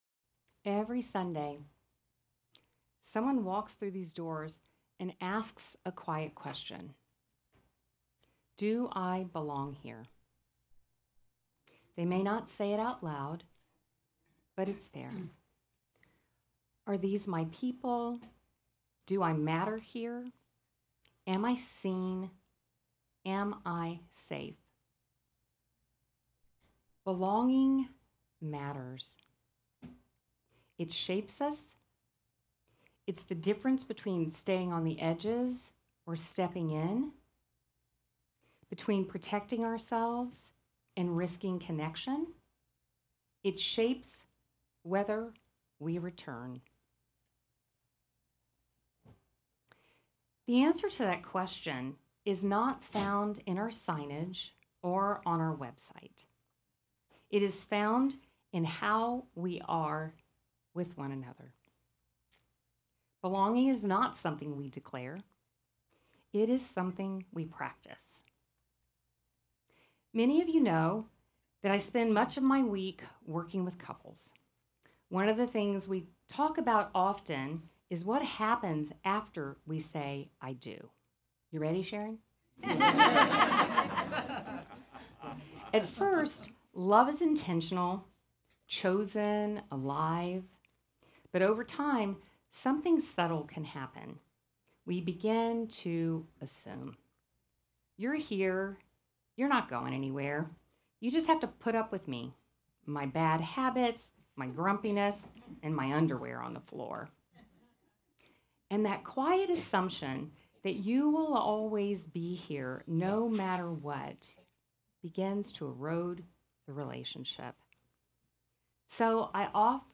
Through music, personal story, and reflections on the spirit of the 1960s, the service explores belonging, courage, and the power of community to heal.